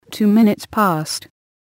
こちらは つづりを入力すると、その通りに話してくれるページです。
そこでAPR9600のAnalogInputにPCのLINEOUTを接続したところ、 適度な音量で再生ができるレベルになりました。